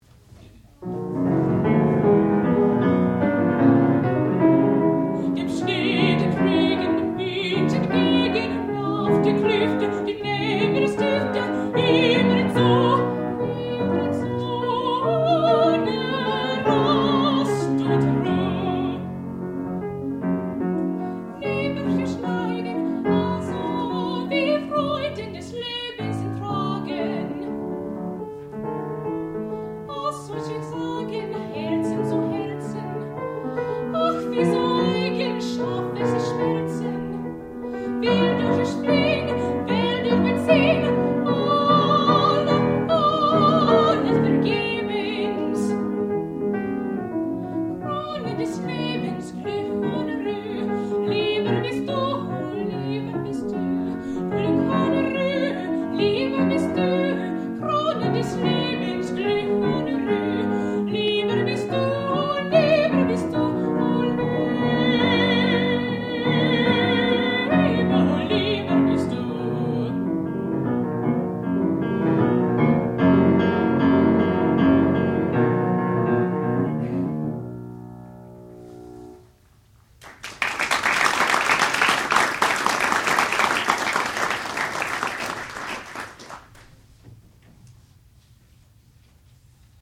sound recording-musical
classical music
mezzo-soprano
piano
Qualifying Recital